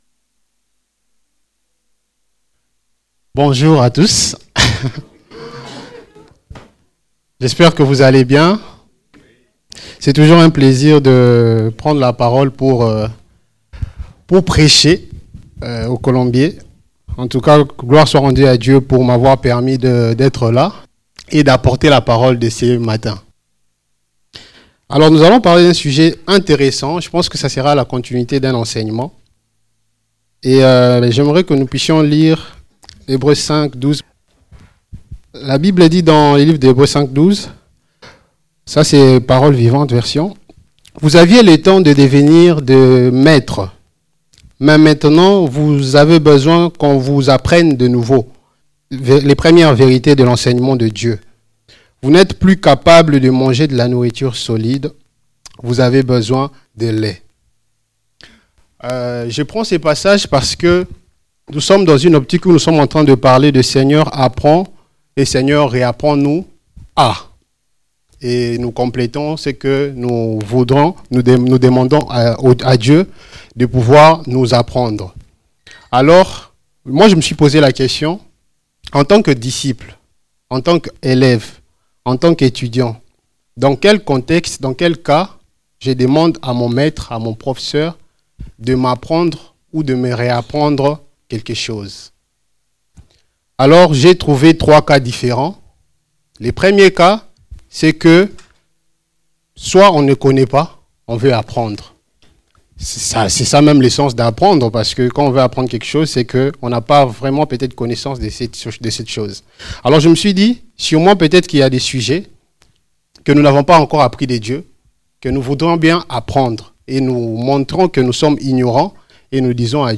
Type De Service: Culte